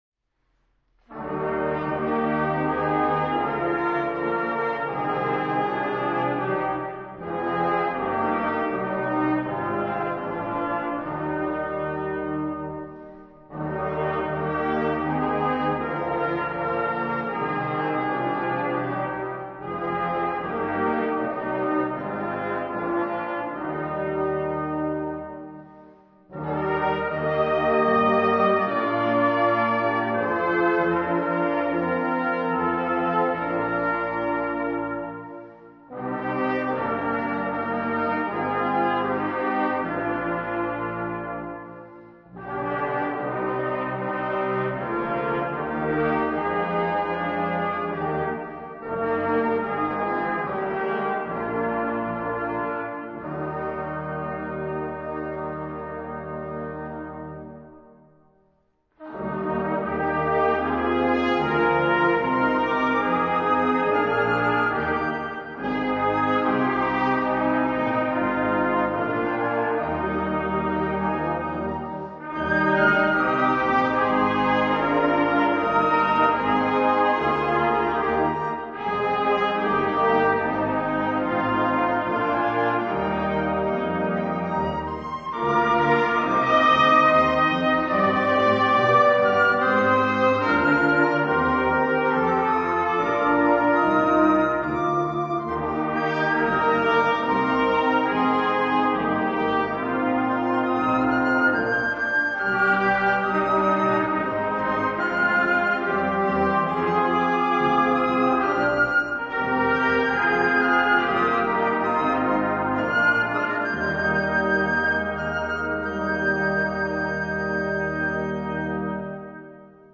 Posaunenchor - Evangelisch-lutherische St.-Martini-Kirchengemeinde Brelingen